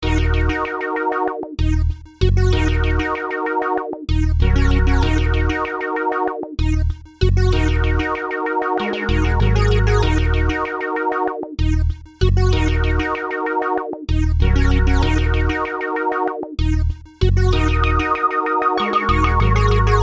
描述：泡沫状的合成器，戴着贝司的手套
Tag: 96 bpm Hip Hop Loops Synth Loops 3.36 MB wav Key : Unknown